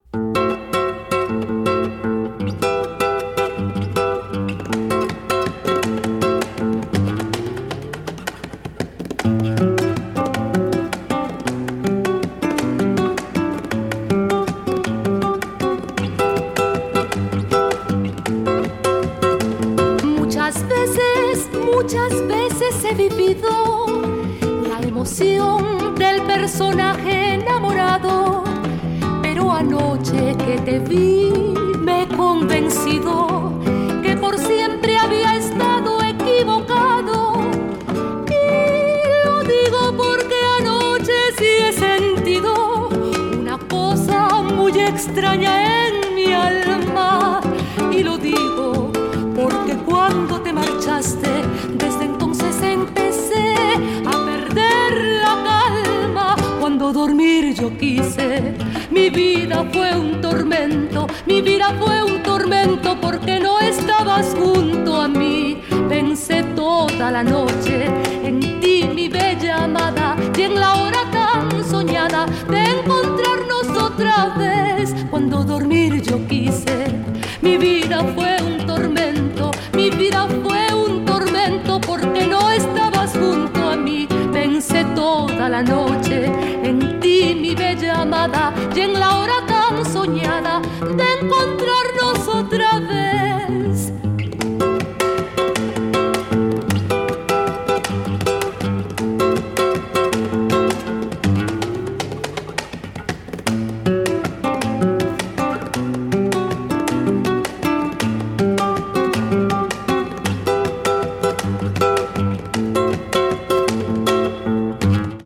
“ムシカ・クリオーヤ”
ギターと歌による作品なので、70年代のメロウなアコースティックSSW作品などが好きな人にもオススメできる好内容ですよ！